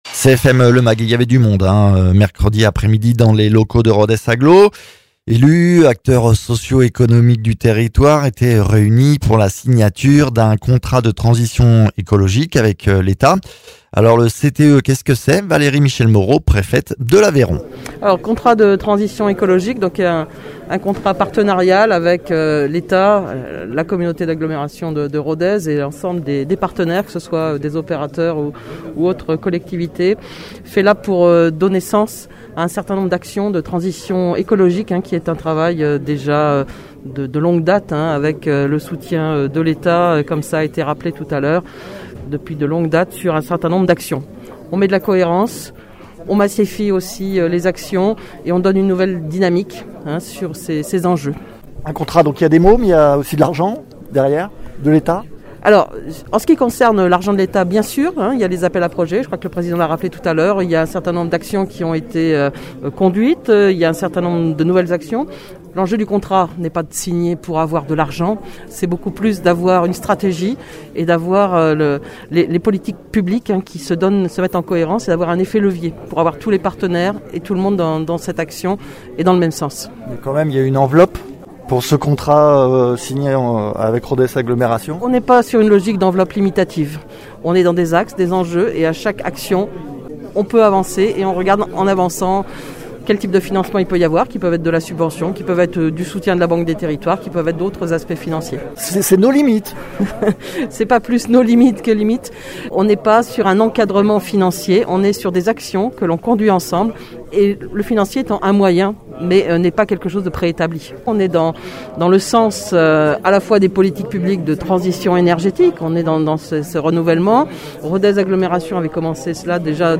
Interviews
Invité(s) : Valérie Michel-Moreaux, préfète de l’Aveyron ; Christian Teyssèdre, Président de Rodez agglomération